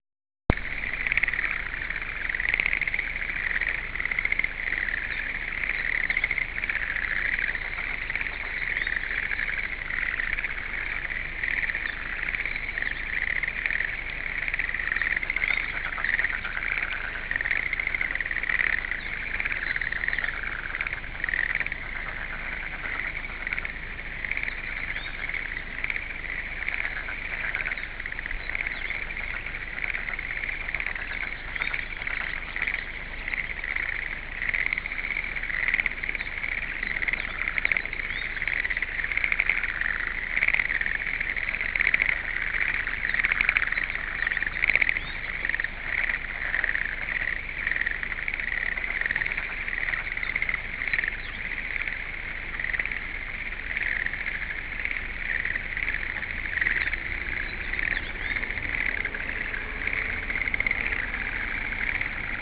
カエルの合唱
これは昨年、新田の40枚の棚田を代掻きした翌日、カラッと晴れ渡っていた早朝に散歩に行って驚いた！
そう、カエルの大合唱です。代掻きで田んぼに満々と水を得たカエルくん達が大喜びをしていたんです。
偶然持ってたデジカメで撮影…ではなく、録音したのがこのコーラス」です。